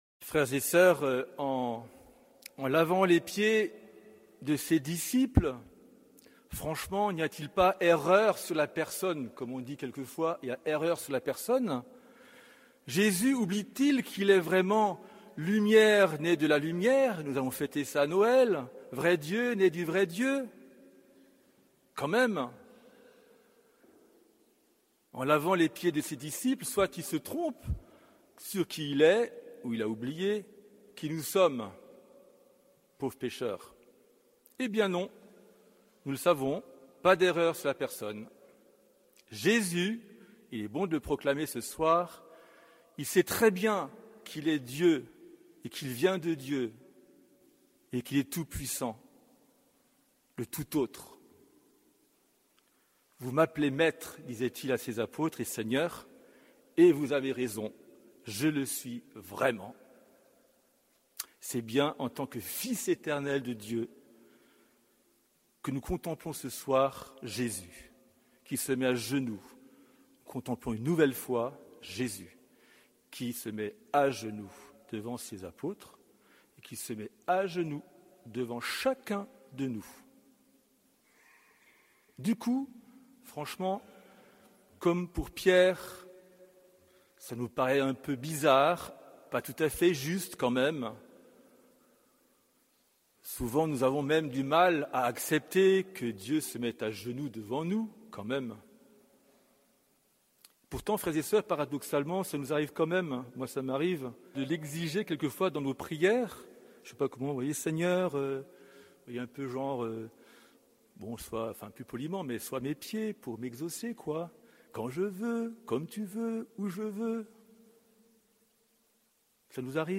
Homélie du jeudi Saint - Sainte Cène